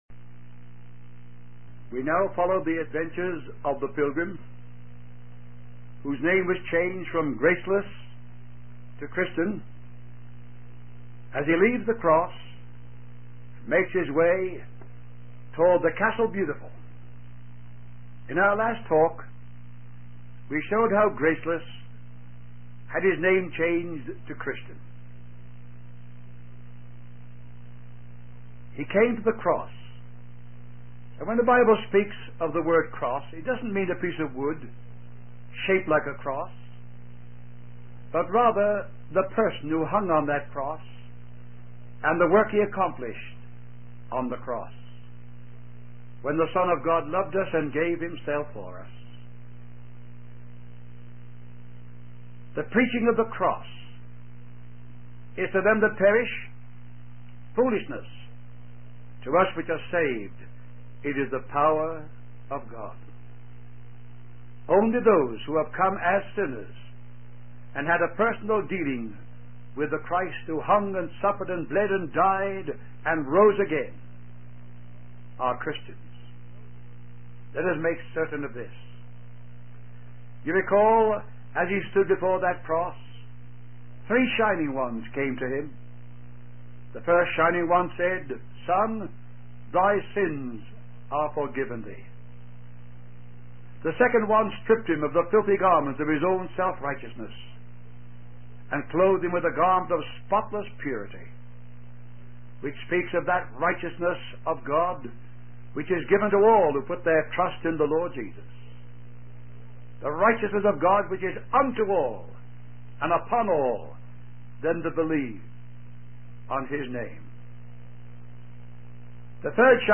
In this sermon, the preacher discusses the journey of a Christian pilgrim named Christian.